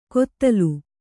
♪ kottalu